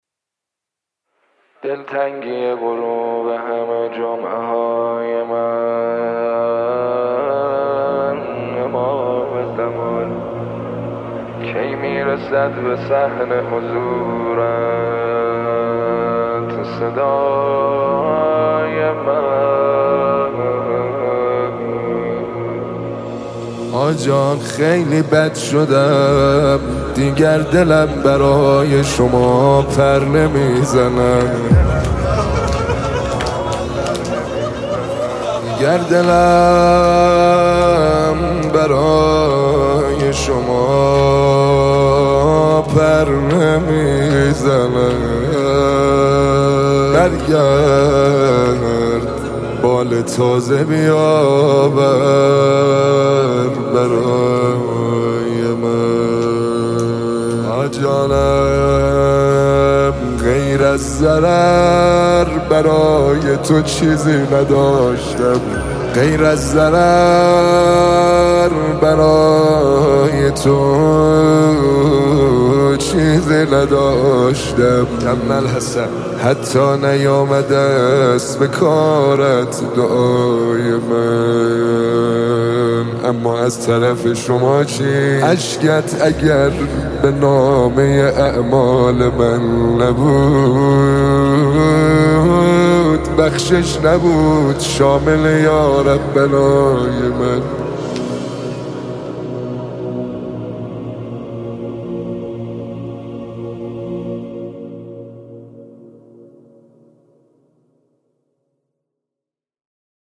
نماهنگ مهدوی